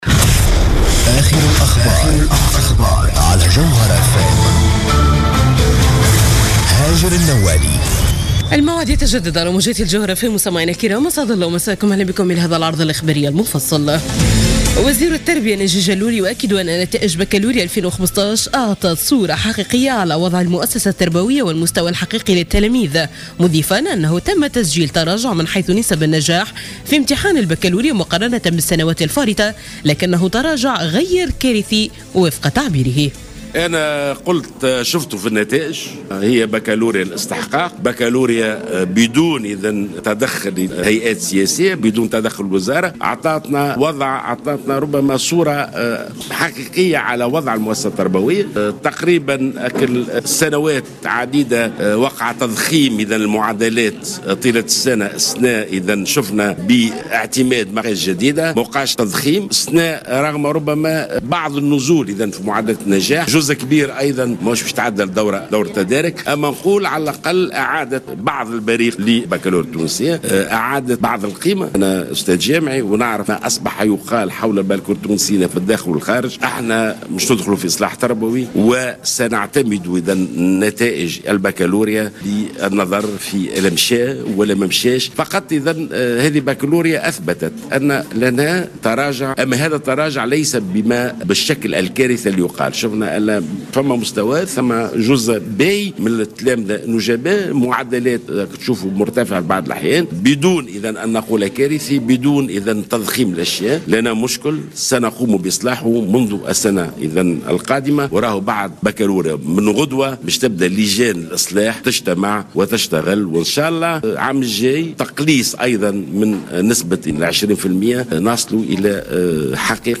نشرة أخبار منتصف الليل ليوم الأحد 21 جوان 2015